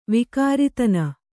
♪ vikāritana